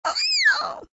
AV_mouse_exclaim.ogg